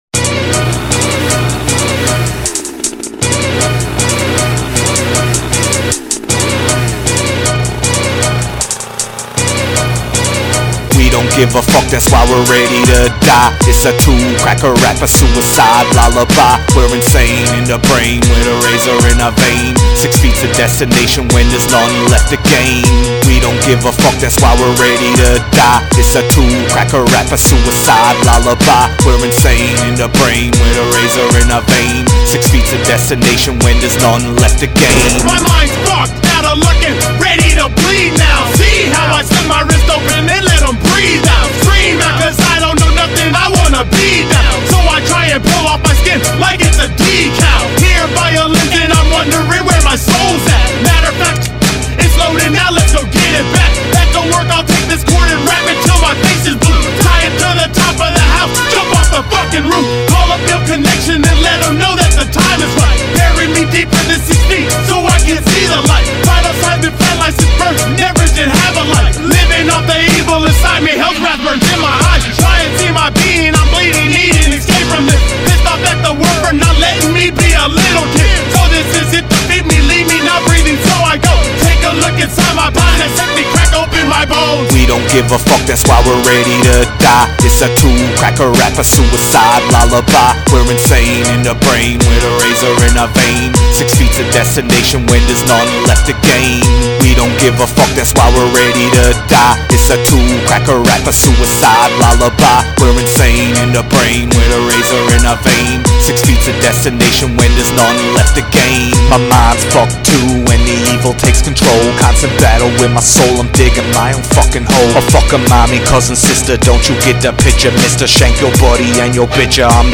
Newfoundland Hip Hop